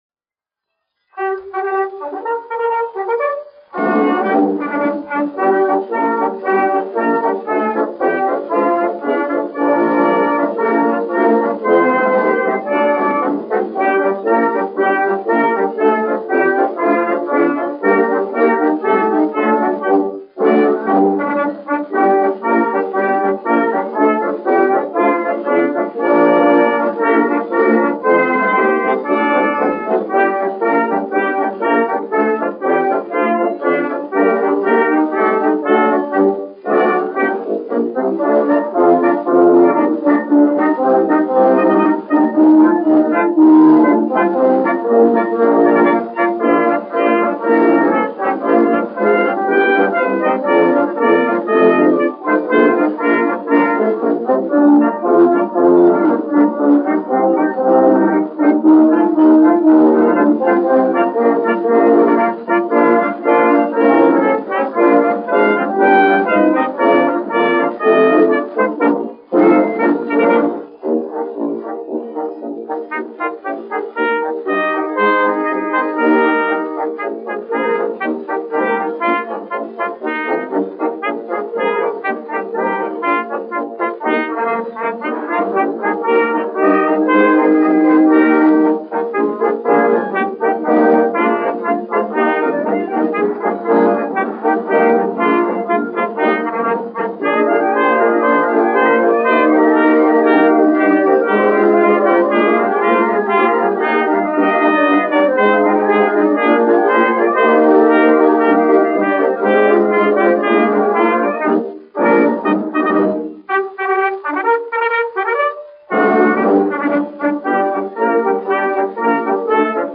1 skpl. : analogs, 78 apgr/min, mono ; 25 cm
Marši
Pūtēju orķestra mūzika
Latvijas vēsturiskie šellaka skaņuplašu ieraksti (Kolekcija)